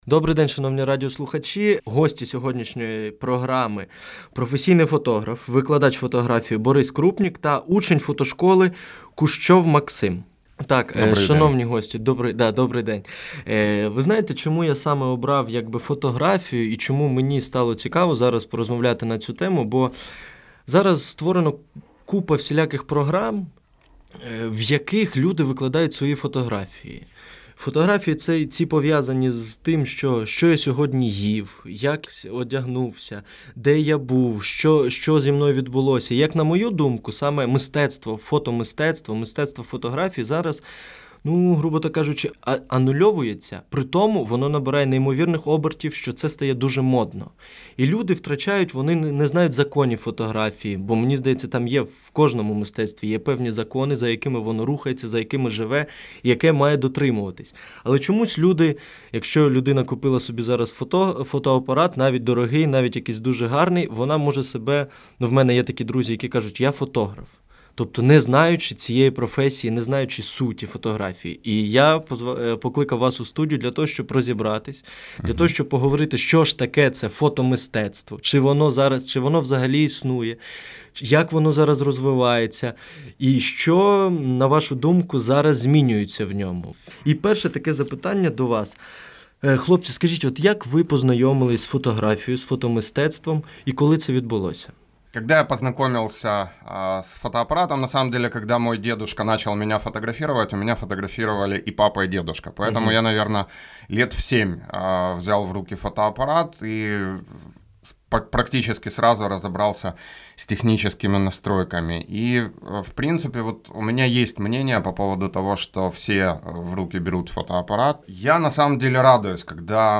Радиоинтервью о фотоискусстве и обучении в сфере фотографии.